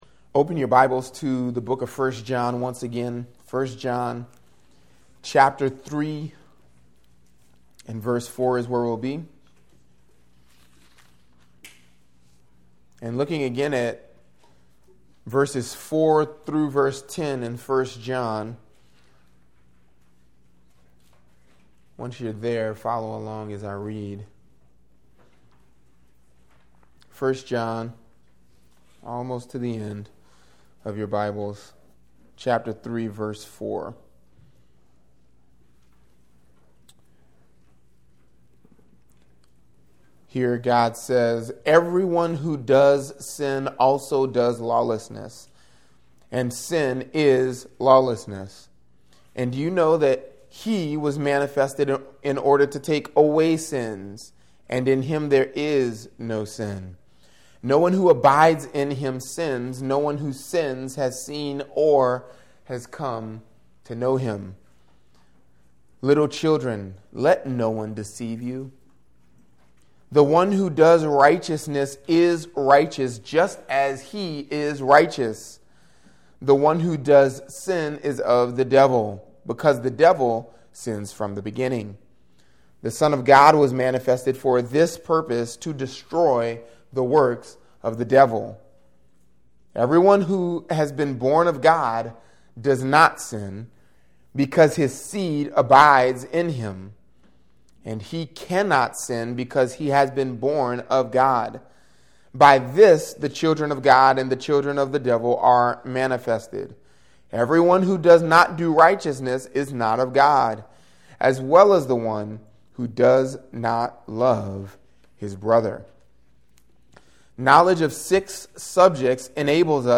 Podcast (gbc-nola-sermons): Play in new window | Download